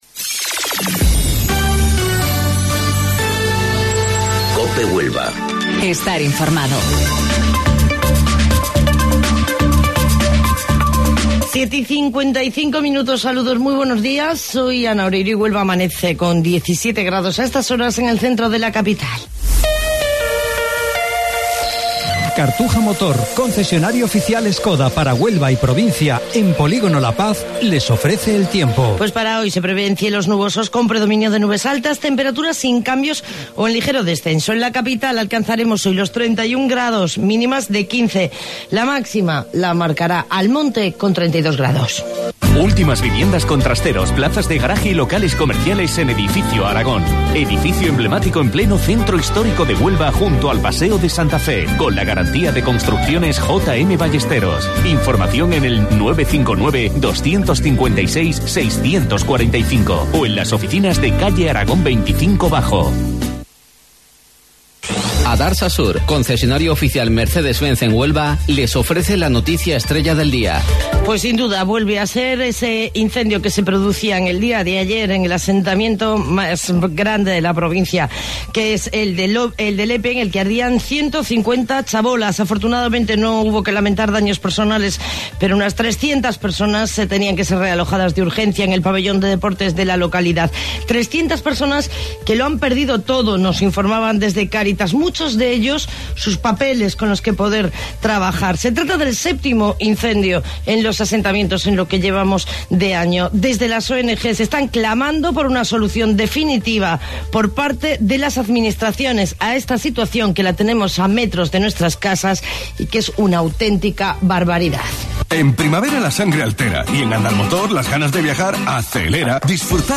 AUDIO: Informativo Local 07:55 del 24 de Mayo